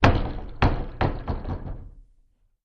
Cellar Door Open and Bounce, Echo